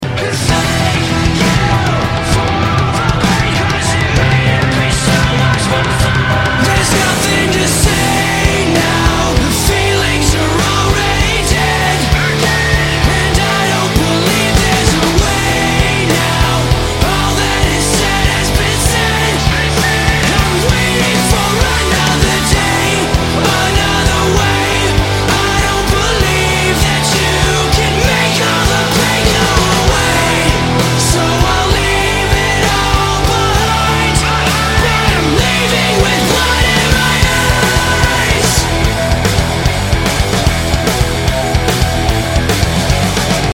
Главная » Файлы » Рок